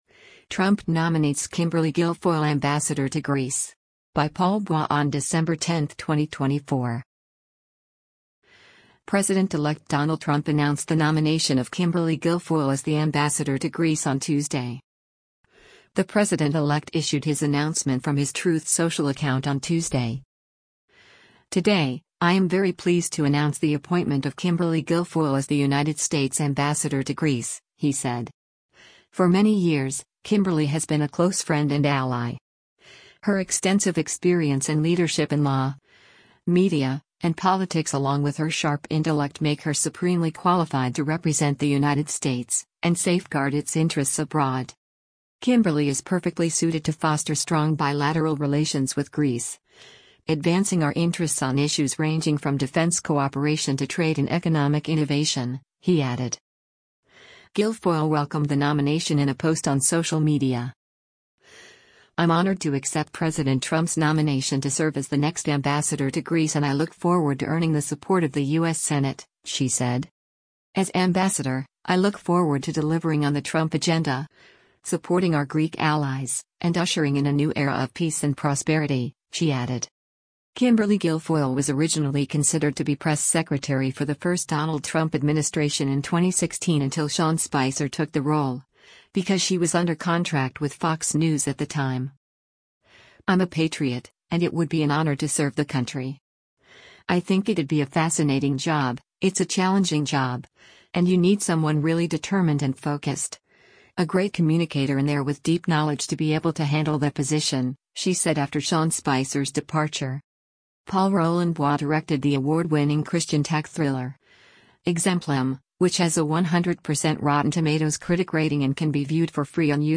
Kimberly Guilfoyle speaks on stage on the third day of the Republican National Convention